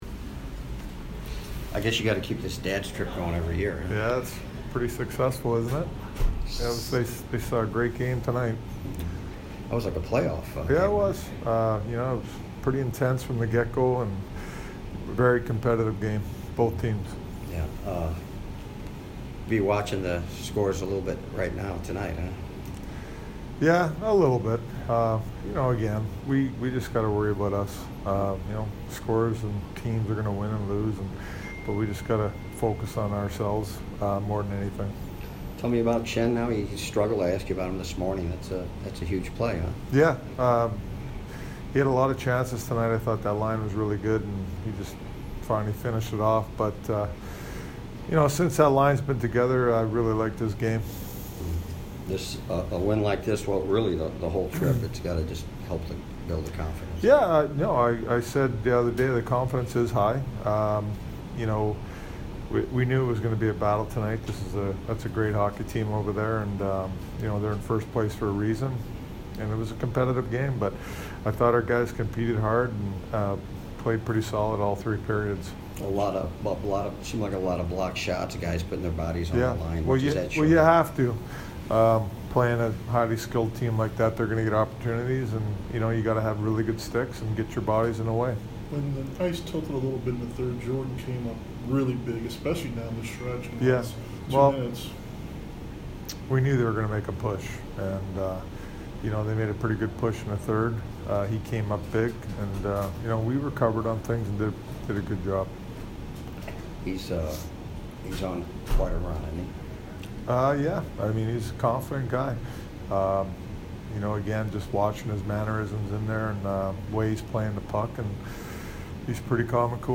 Craig Berube post-game 2/7